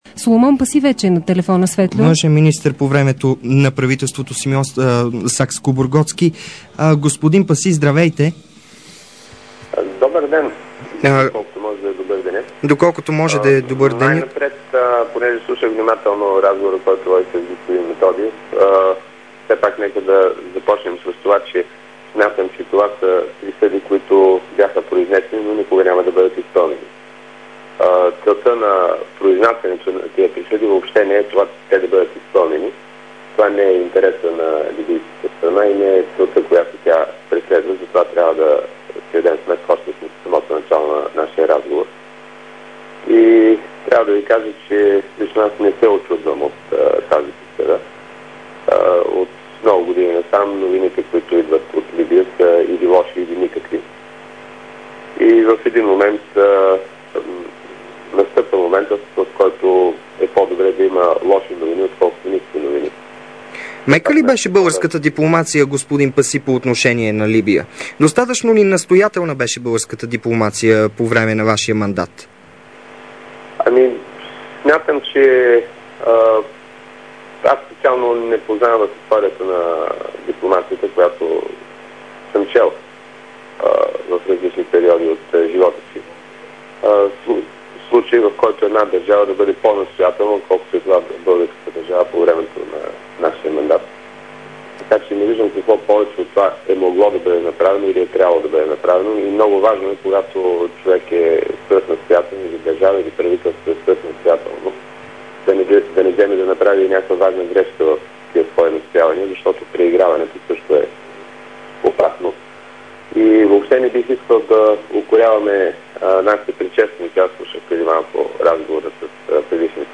Интервю със Соломон Паси-19.12.2007